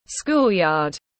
Sân trường tiếng anh gọi là schoolyard, phiên âm tiếng anh đọc là /ˈskuːl.jɑːd/
Schoolyard /ˈskuːl.jɑːd/
Schoolyard.mp3